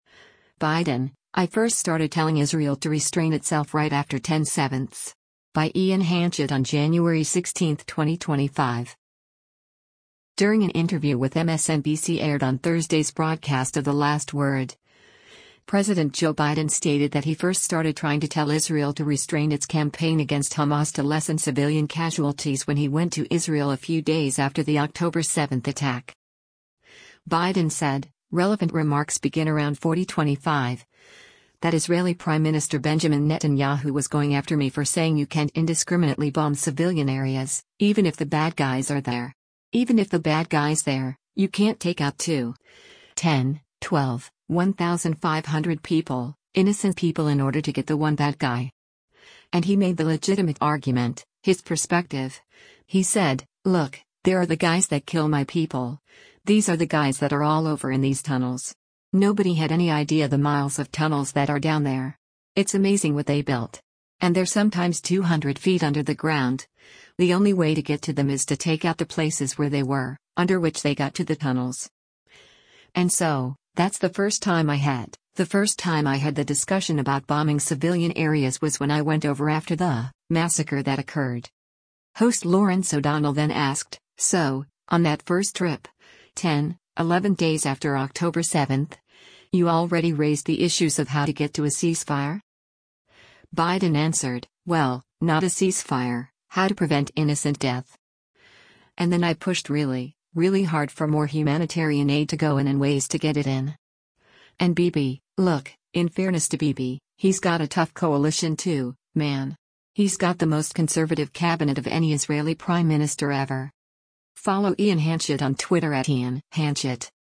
During an interview with MSNBC aired on Thursday’s broadcast of “The Last Word,” President Joe Biden stated that he first started trying to tell Israel to restrain its campaign against Hamas to lessen civilian casualties when he went to Israel a few days after the October 7 attack.
Host Lawrence O’Donnell then asked, “So, on that first trip, ten, eleven days after October 7, you already raised the issues of how to get to a ceasefire?”